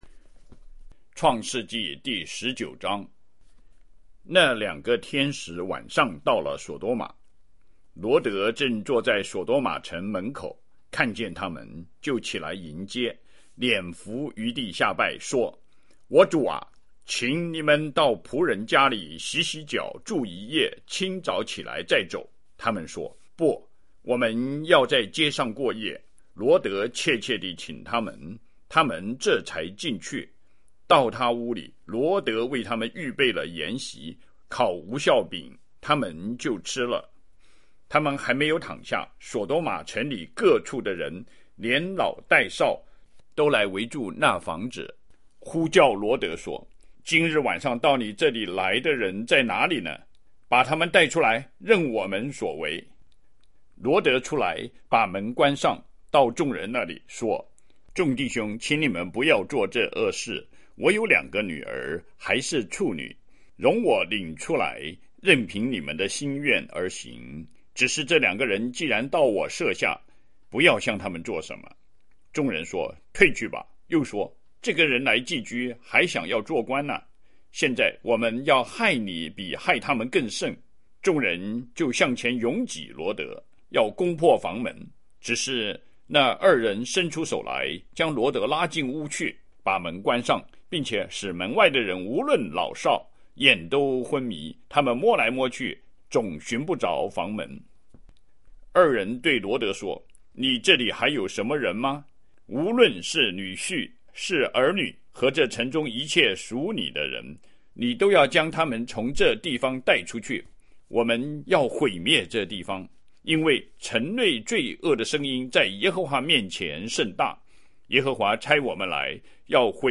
BibleReading407.mp3